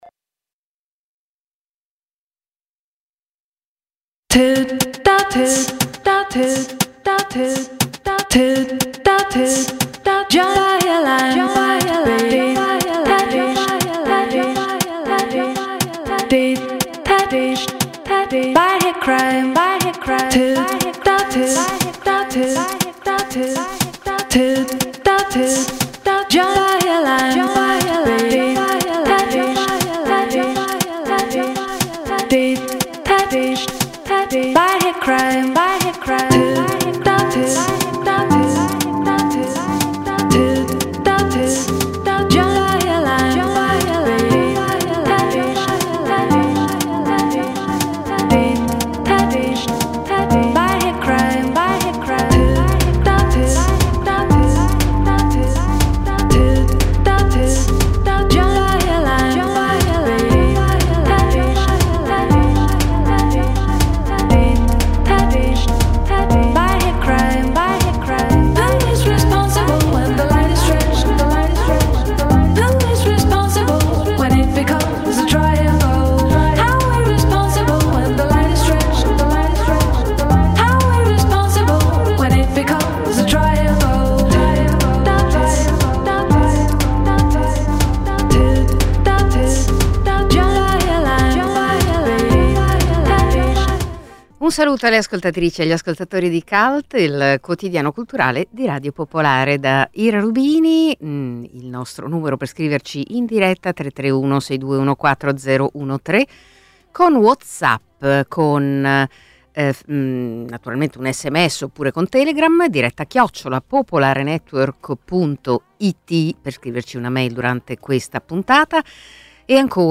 Cult è il quotidiano culturale di Radio Popolare, in onda dal lunedì al venerdì dalle 11.30 alle 12.30.